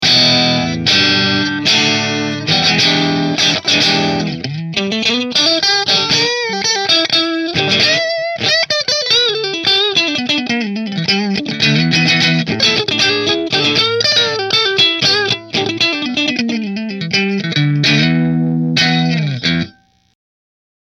Fender Partscaster Position 2 Through Marshall